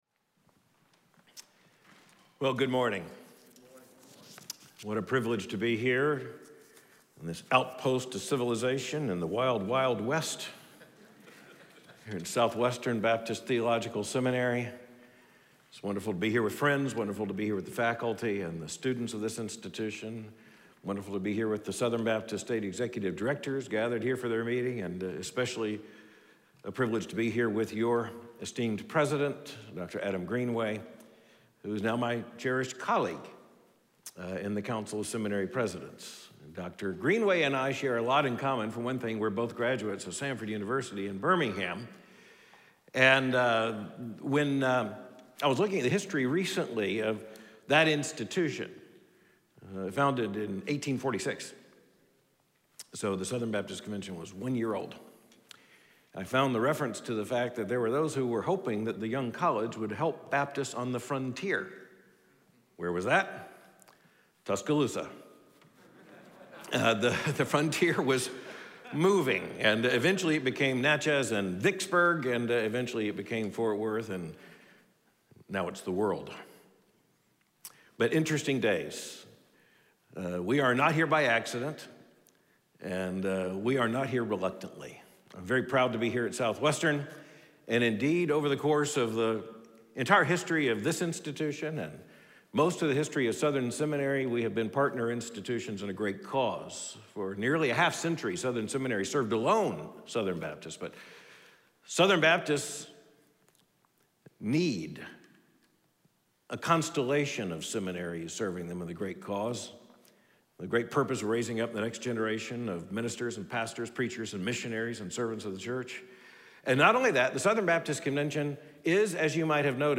President Albert Mohler of Southern Baptist Theological Seminary preaches on Luke 18:1-8.